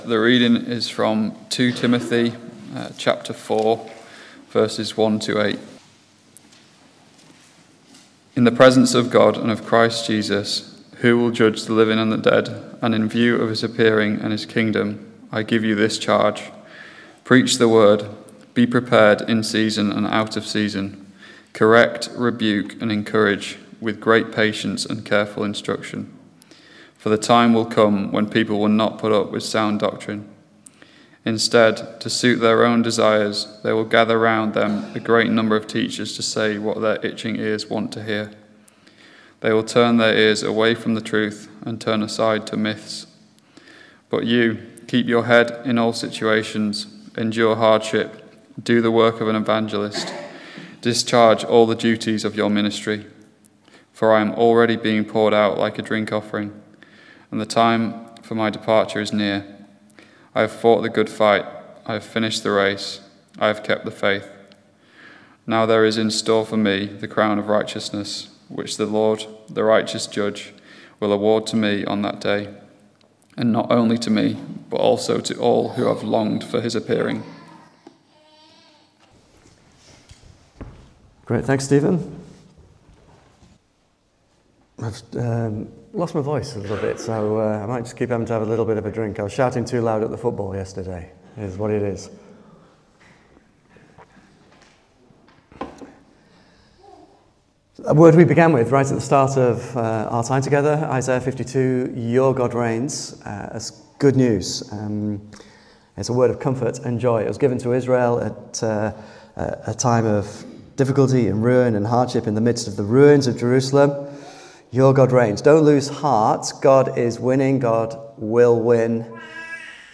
Service Type: Morning Service 9:15